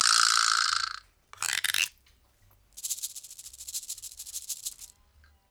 88-PERC10.wav